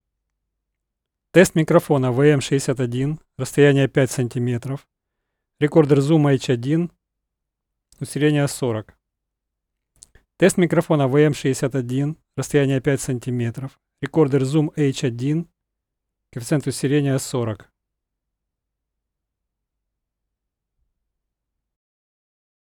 По шумам они меня не впечатлили хочу написать отзыв продавцу.
Для конкретики звук того и другого, без эквализации, только нойзгейт Вложения test_WM-61A_ebay+ReaGate.mp3 test_WM-61A_ebay+ReaGate.mp3 713,5 KB · Просмотры: 994 test_petlychka+ReaGate.mp3 test_petlychka+ReaGate.mp3 922,4 KB · Просмотры: 929